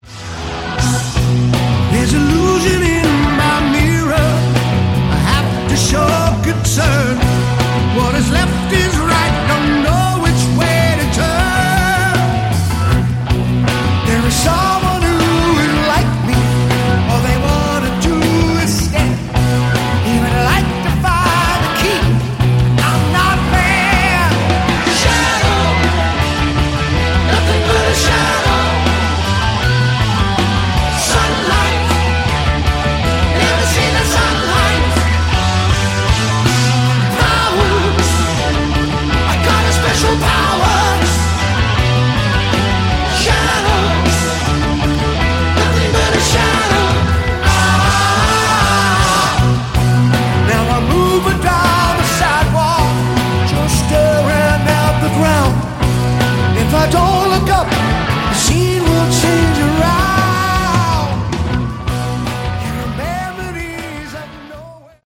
Category: Classic Hard Rock
vocals
guitar
keyboards
bass
drums